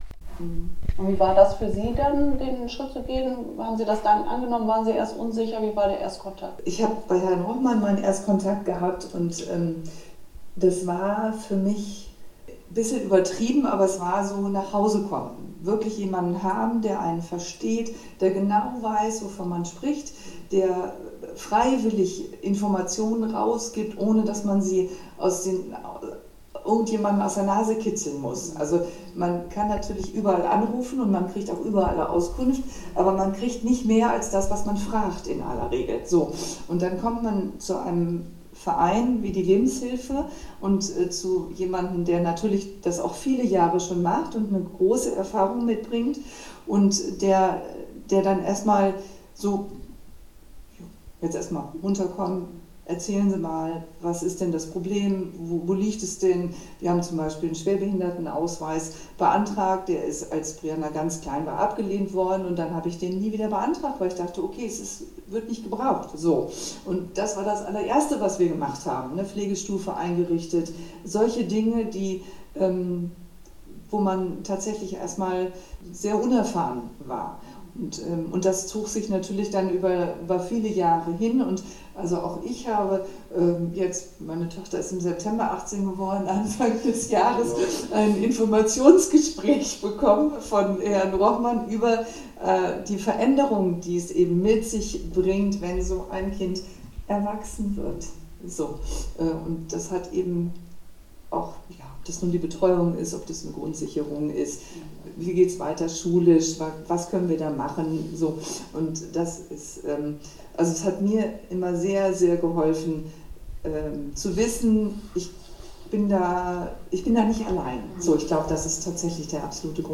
Eine Mutter erzählt, was ihr Beratung bedeutet